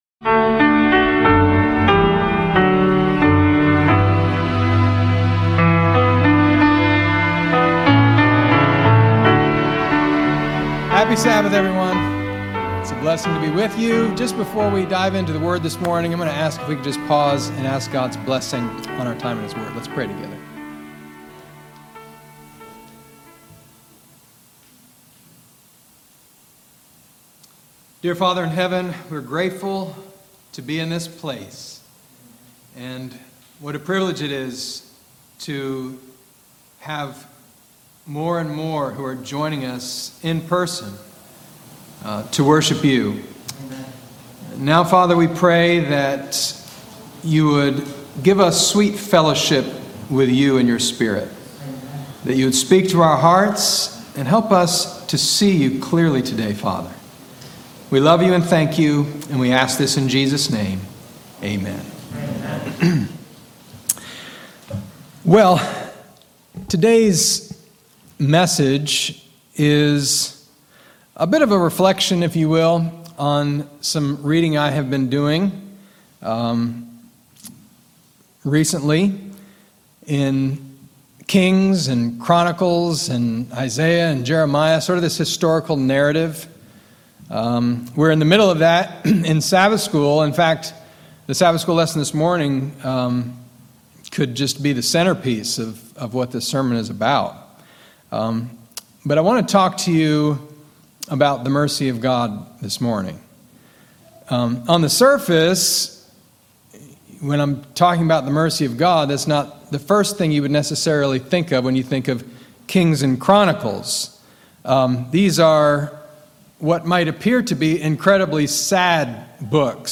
Discover the transformative power of self-reflection, God's unfailing mercy, and the Holy Spirit’s work in this inspiring sermon. Dive into biblical truths, embrace hope, and learn how unity, compassion, and the mission of sharing the gospel can bring healing to a divided world.